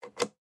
На этой странице собраны звуки работы шредера — от плавного жужжания до резкого измельчения бумаги.
Звук полного отключения шредера